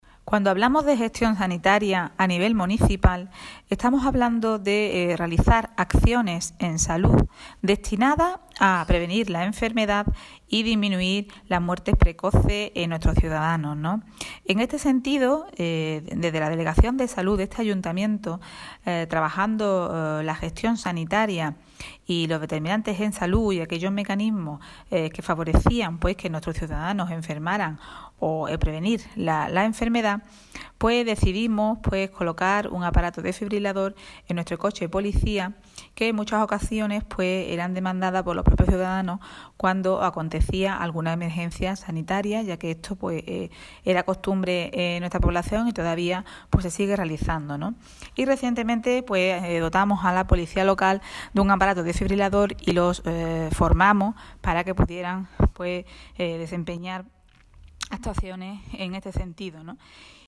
La sanitaria y delegada de salud del Ayuntamiento de Ubrique, Virginia Bazán destacó en la SER, la importancia de contar con un equipo desfibrilador en el coche de la Policía Local: